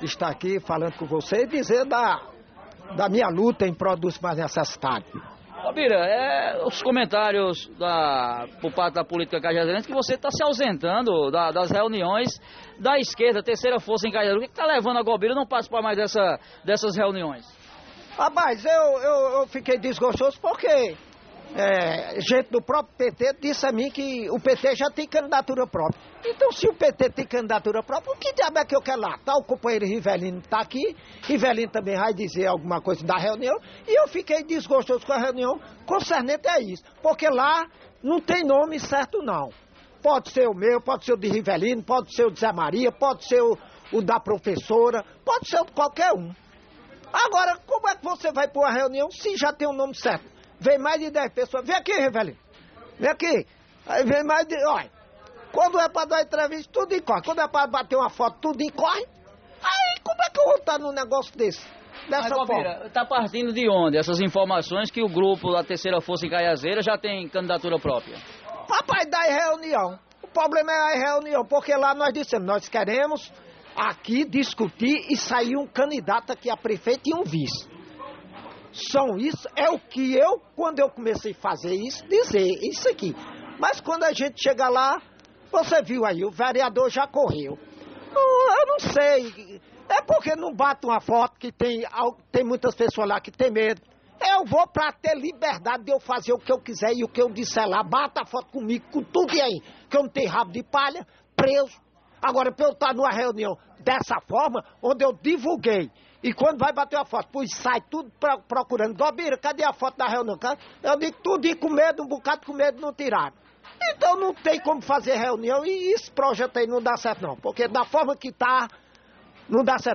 Em entrevista no programa Rádio Vivo da Alto Piranhas desta quinta